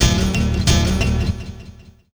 HM90GTR1  -R.wav